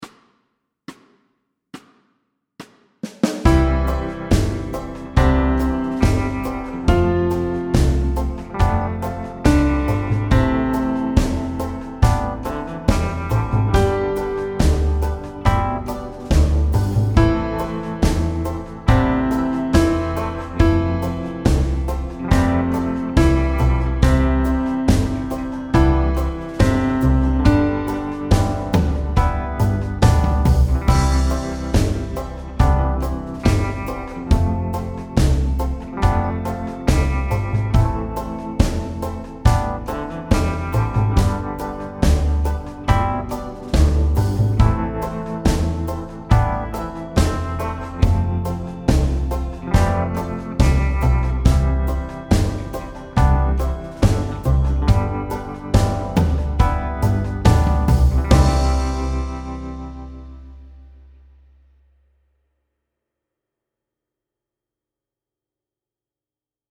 Medium C instr (demo)